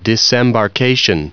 Prononciation du mot disembarkation en anglais (fichier audio)
Prononciation du mot : disembarkation